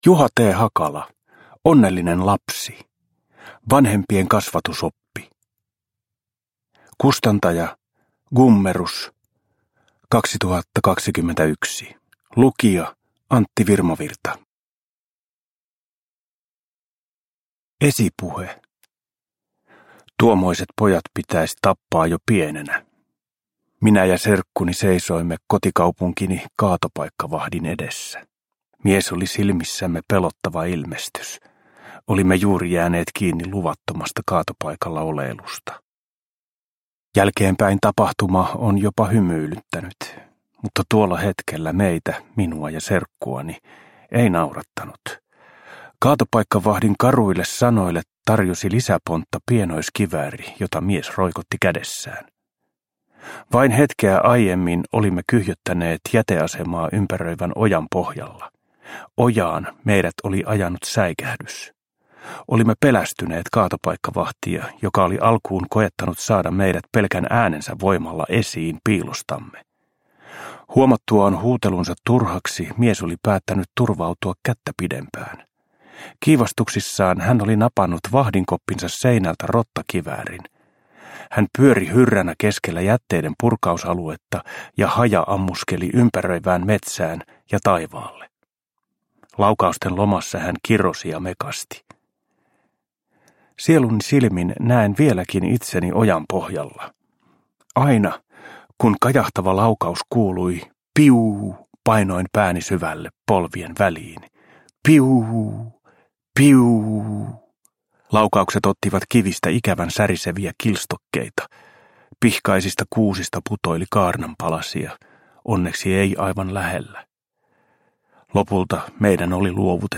Onnellinen lapsi – Ljudbok – Laddas ner